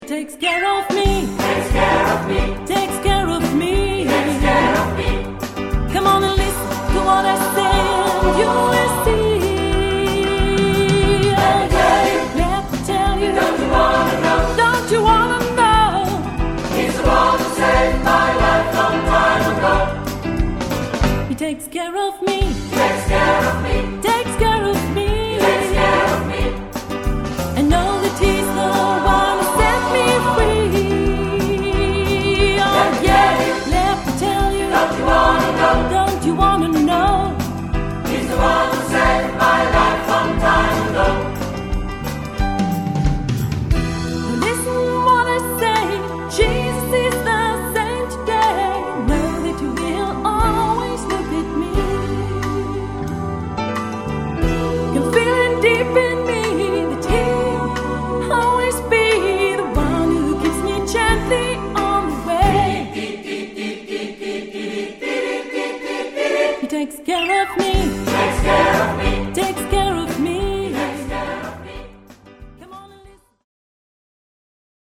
Die CD entstand wieder in einem Tonstudio